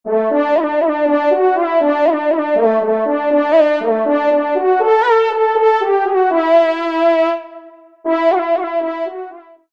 FANFARE
Pupitre de Chant